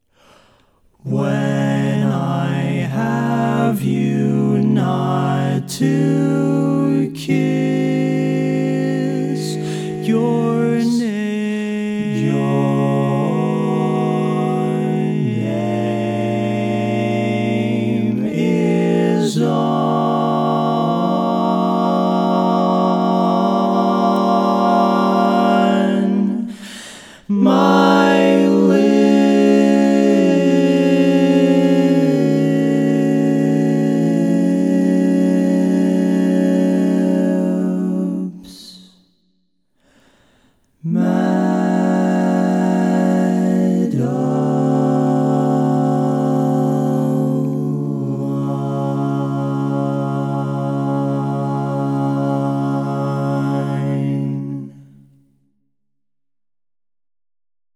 Key written in: C Major
How many parts: 4
Type: Barbershop
All Parts mix: